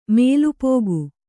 ♪ mēlu pōgu